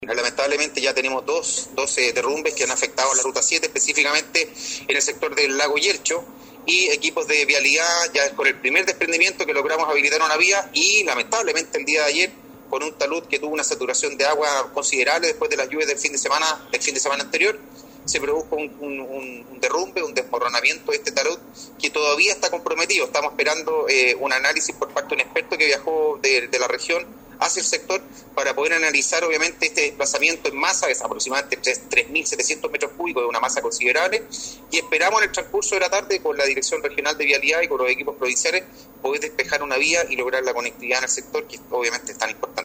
El seremi del Mop, Yeims Frai, expuso que la situación es delicada por cuanto esa ladera del cerro no está consolidada, y existe gran cantidad de material muy suelto, que podría perjudicar las tareas de apertura.